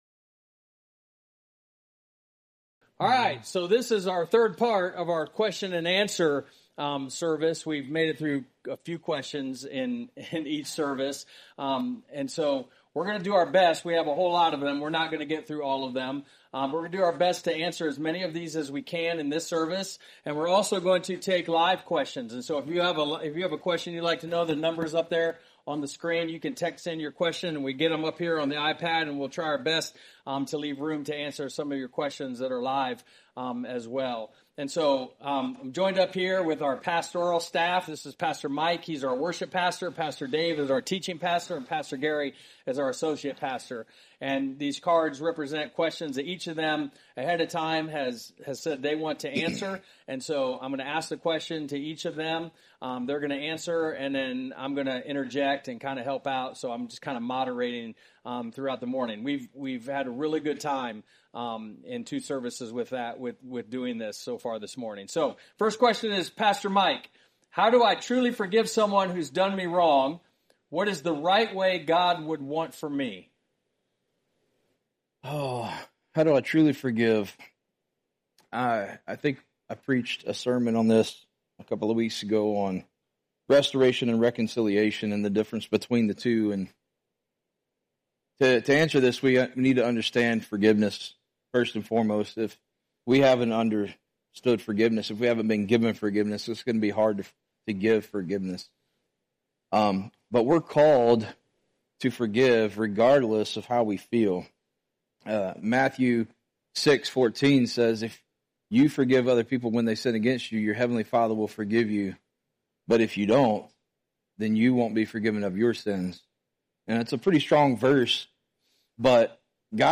Q&A - 11:00 service
This week, we had a special presentation of questions and answers by our pastors.